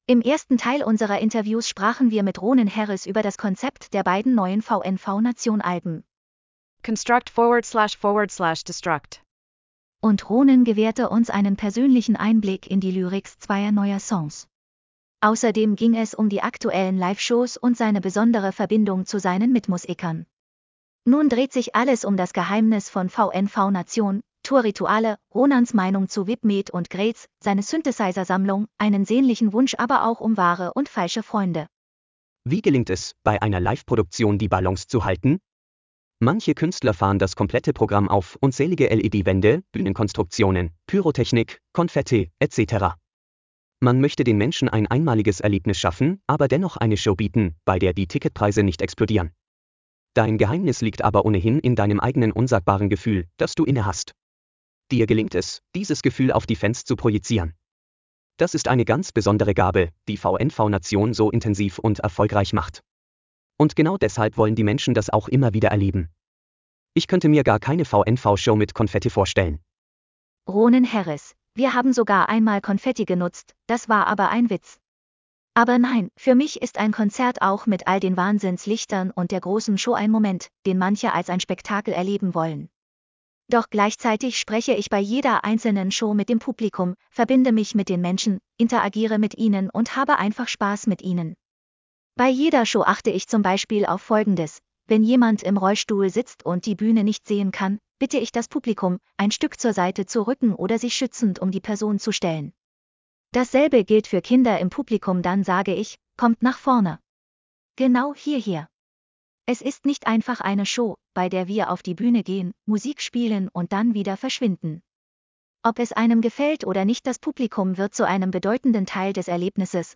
Nun dreht sich alles um das Geheimnis von VNV Nation, Tour-Rituale, Ronans Meinung zu VIP Meet & Greets, seine Synthesizer-Sammlung, einen sehnlichen Wunsch aber auch um wahre und falsche Freunde. Lass Dir den Beitrag vorlesen: /wp-content/TTS/190397.mp3 Wie gelingt es, bei einer Live-Produktion die Balance zu halten?